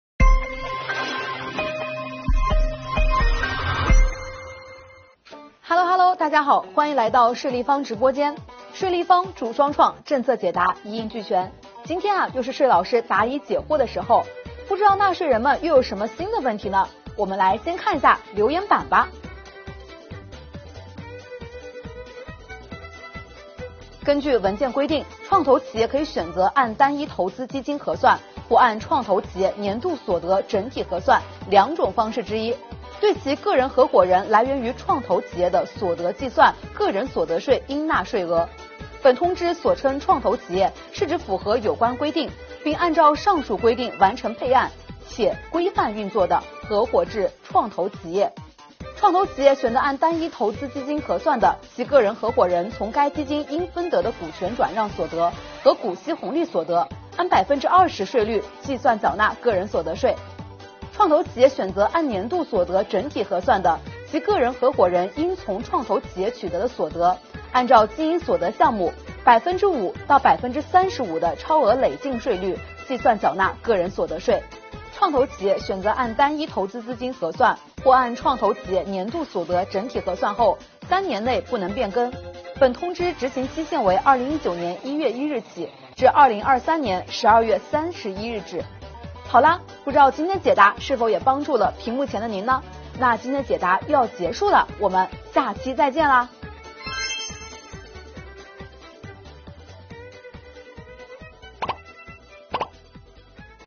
今天，税老师为您讲解：创业投资企业个人合伙人来源于创投企业的所得如何计缴个人所得税？